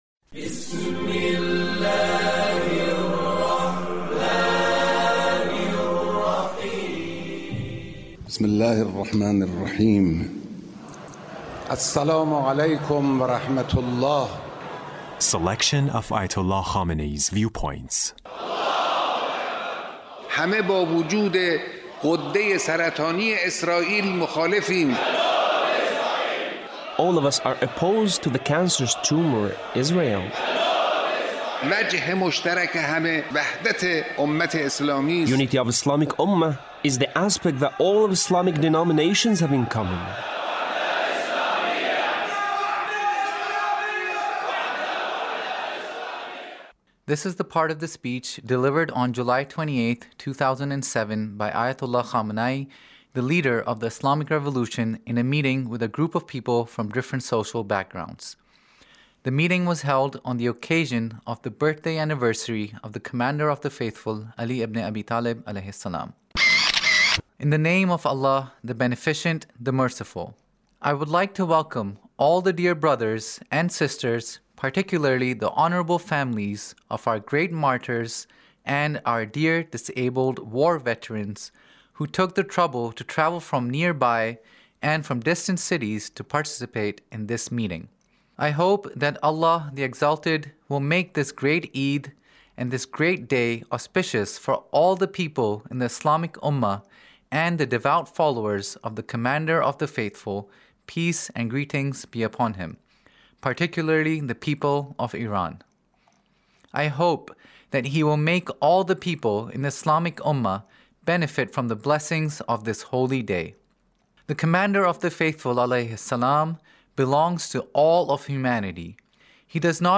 Leader's Speech about Imam Ali's Character